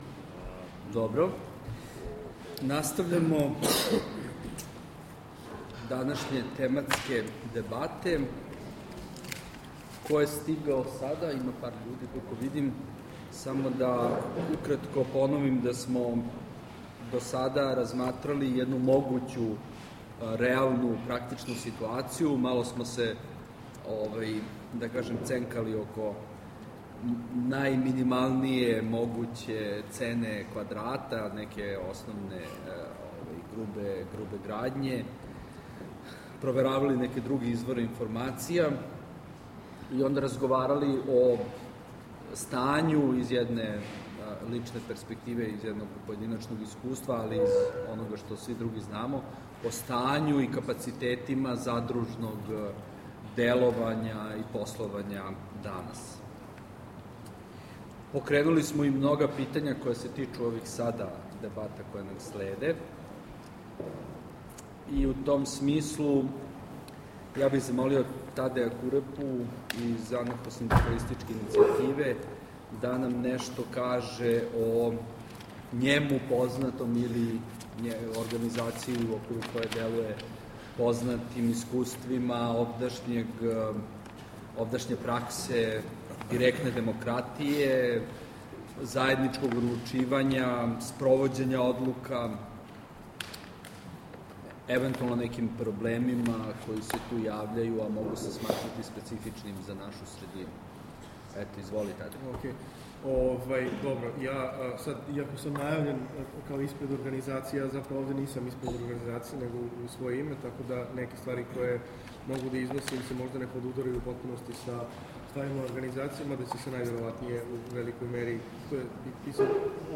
Otvoreni razgovor o ulozi gradskih entuzijasta i majstora